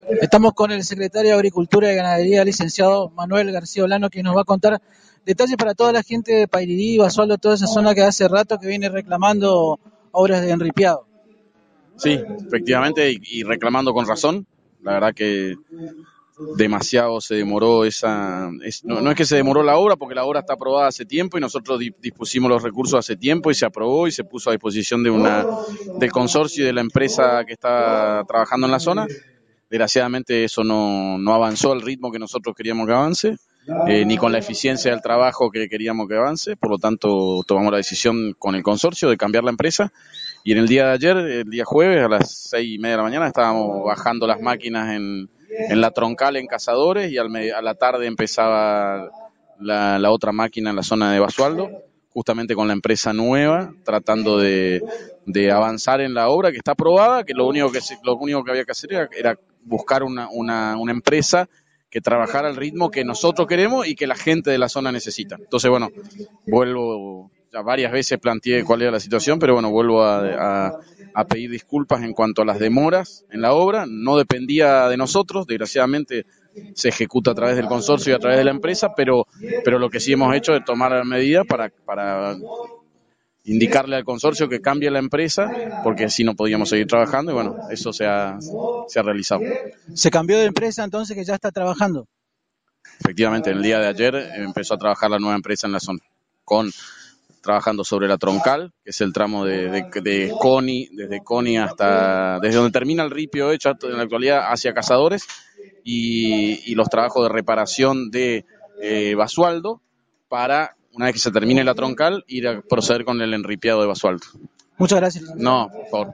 (Audio) El licenciado Manuel García Olano, secretario de agricultura y ganadería del Gobierno de la Provincia confirmó en Agenda 970 a través de la AM 970 Radio Guarani que el martes comenzaron con las obras de enripiado en las zonas de Cazadores rumbo a Basualdo y en la zona de la Ruta 25 en "El Descanso".
Escuchá la nota con el Licenciado García Olano